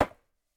Golpe de piedra contra una calabaza
Sonidos: Acciones humanas